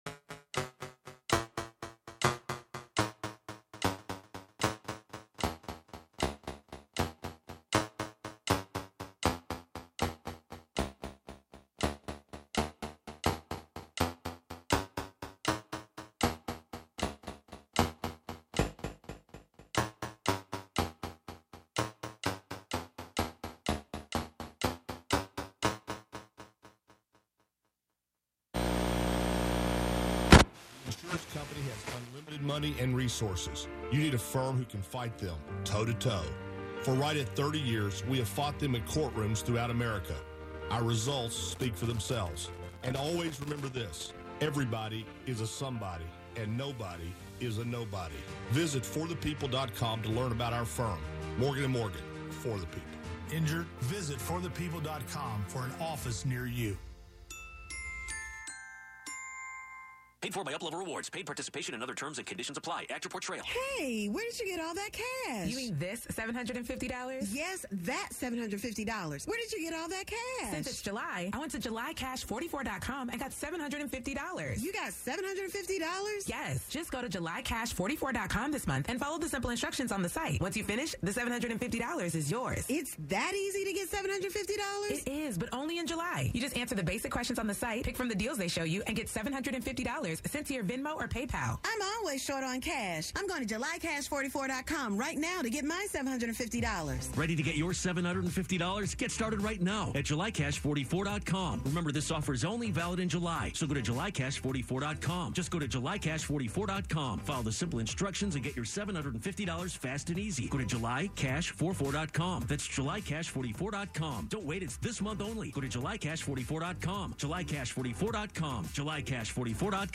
Live from Brooklyn, New York,
instant techno